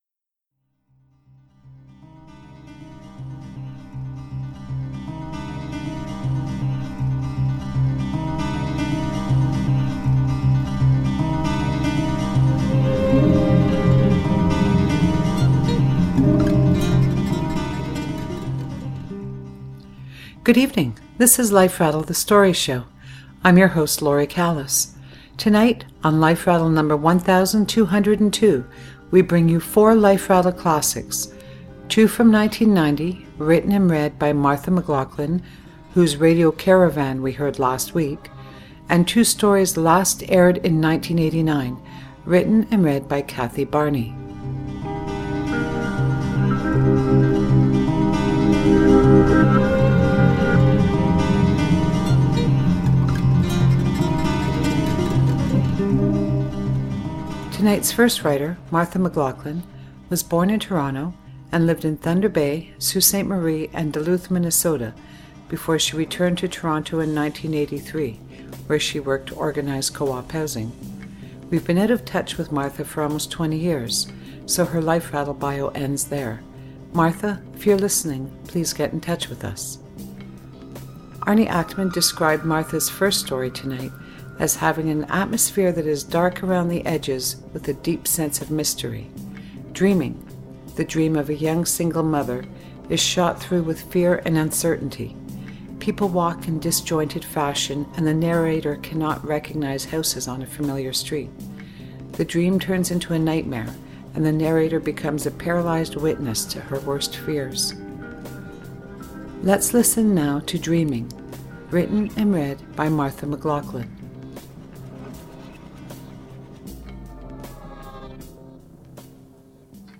reading her stories